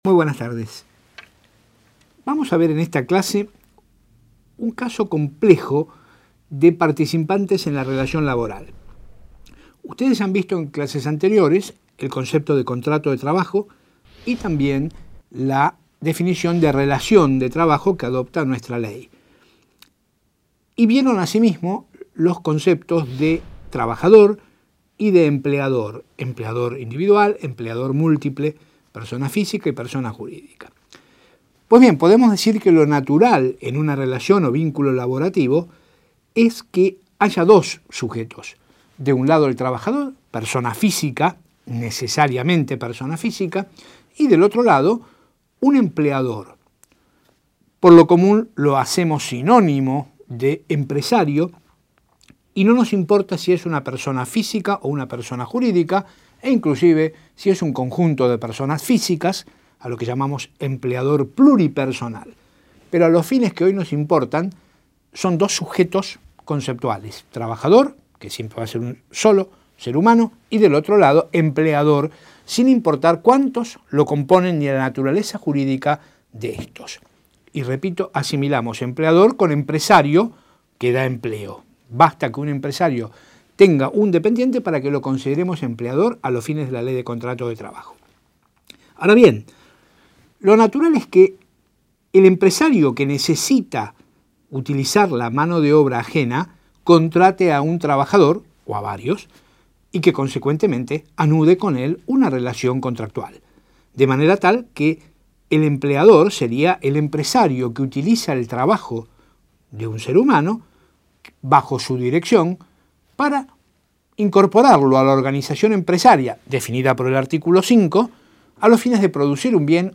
Audio de la clase